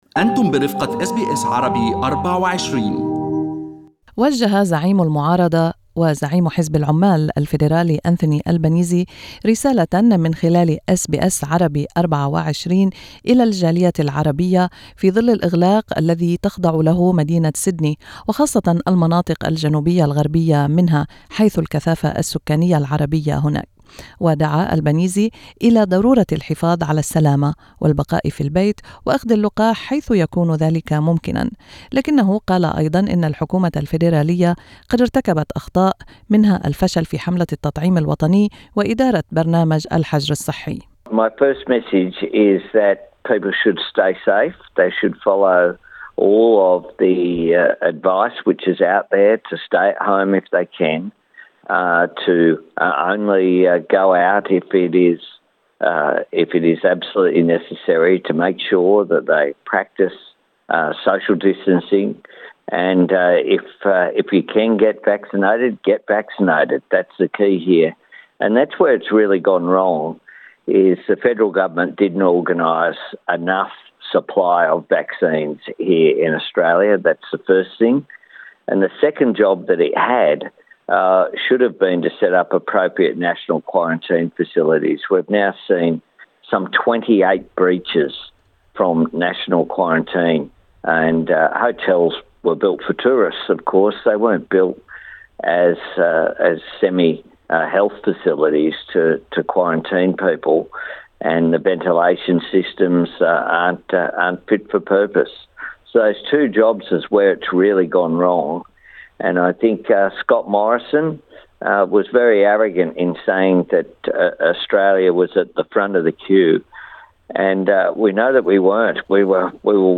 زعيم المعارضة العمالية أنثوني ألبانيزي في حديث خاص مع أس بي أس عربي 24 Source: SBS